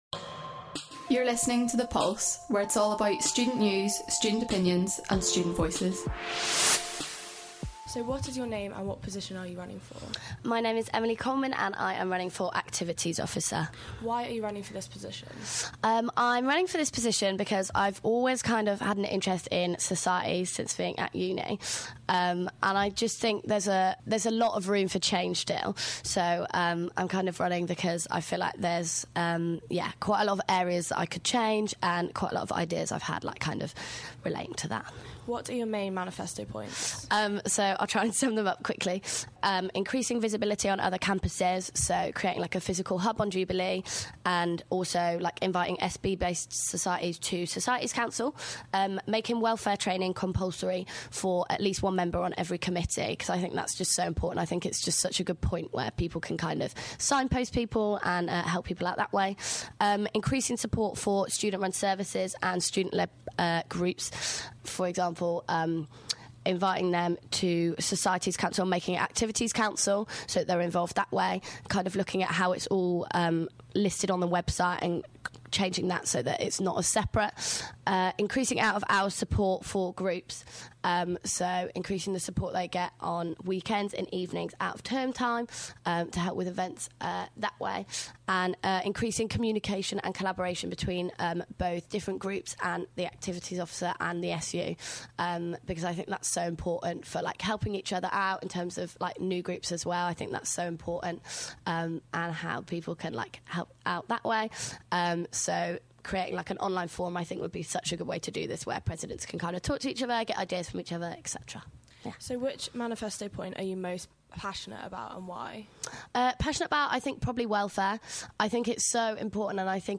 We interviewed this year's SU Activities Officer Candidates.